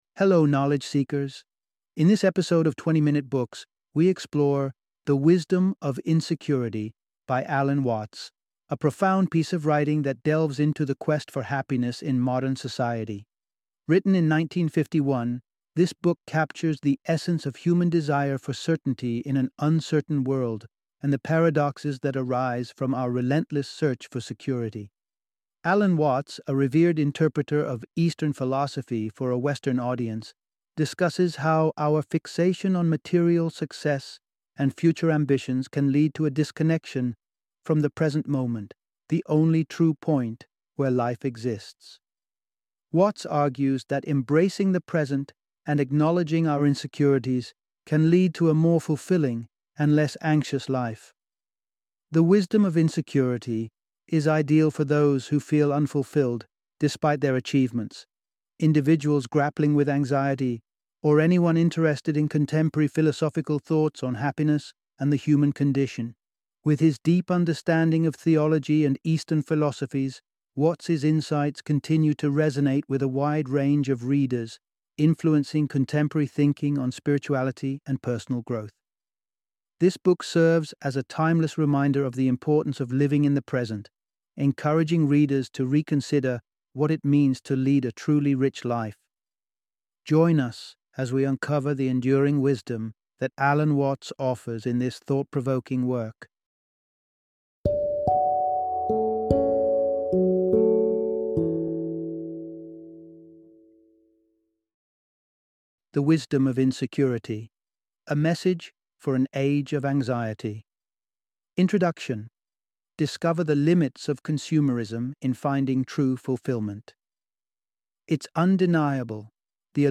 The Wisdom of Insecurity - Audiobook Summary